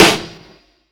Snares
LIVEO_SNR_2.wav